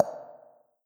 notification sounds